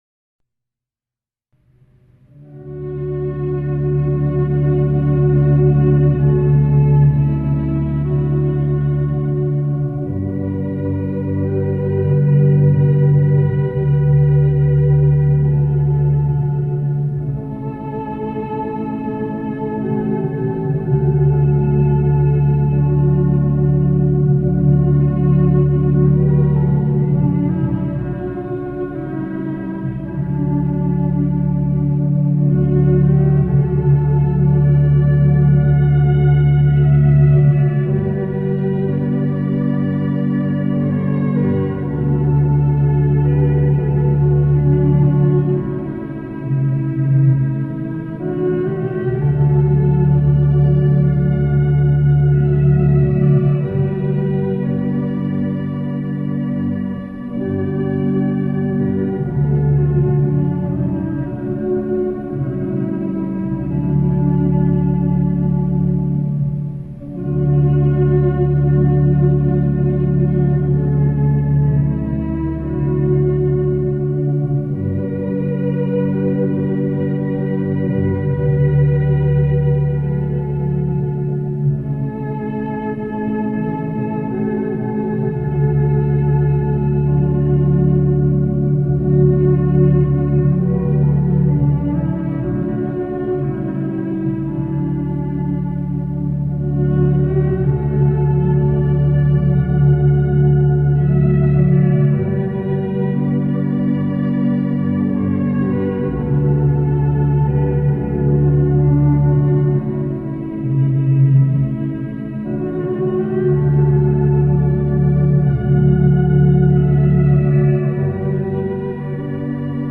INNI ROSACROCIANI
INNO DI CHIUSURA